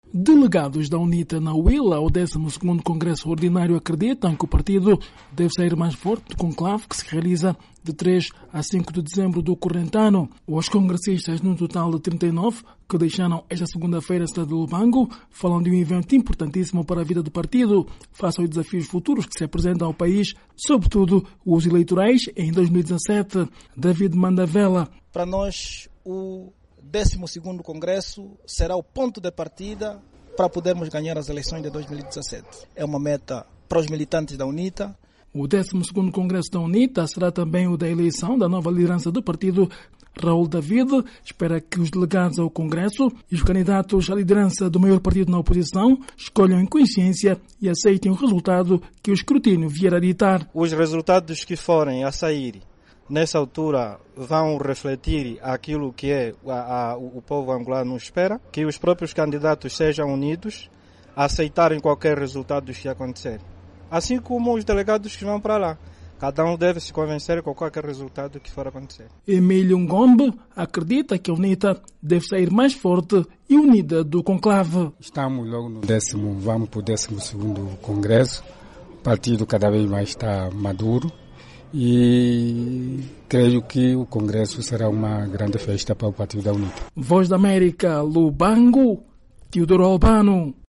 Huíla : Delegados da UNITA falam sobre congresso - 1:30